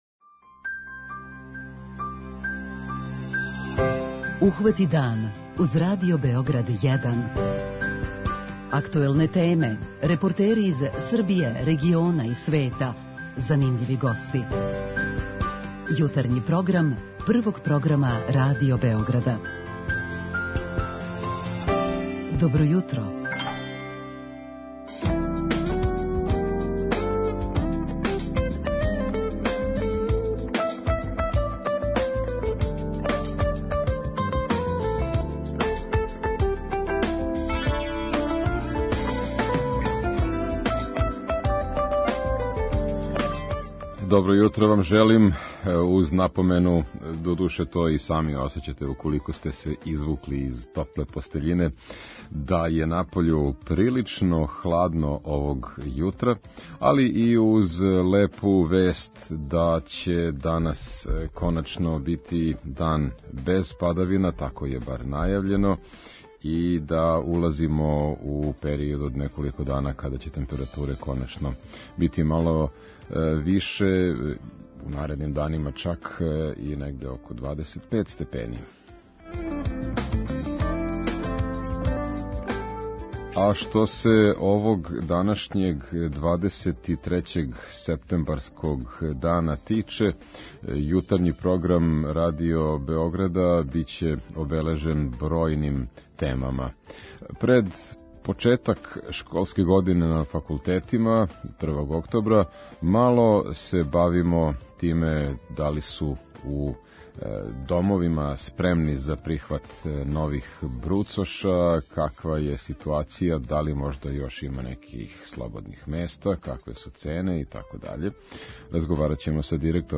У сусрет почетку нове школске године на факултетима разговарамо с представником Студентског центра Београд о томе да ли су студентски домови спремни за нове становнике, да ли има још места и које су цене становања ове године.